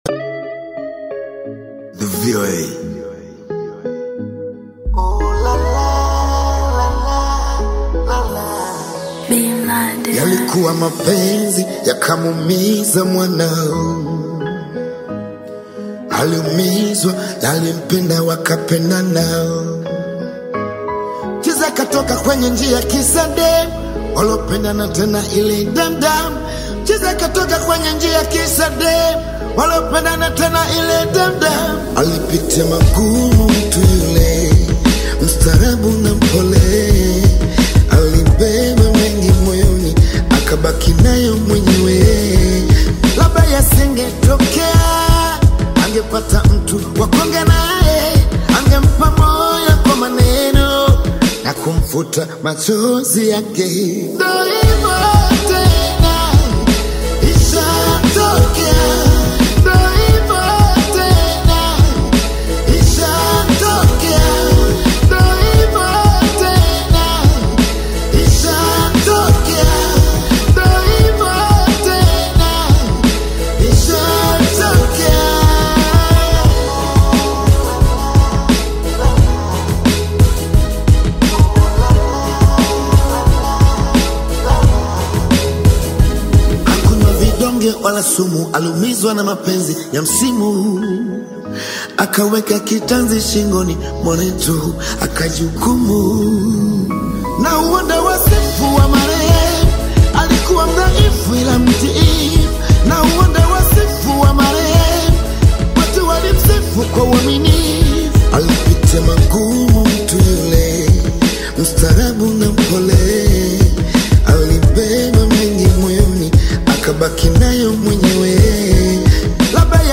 With touching lyrics and a calm, reflective tone